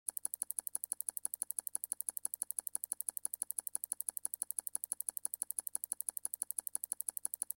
tile cube. click at your sound effects free download